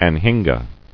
[an·hin·ga]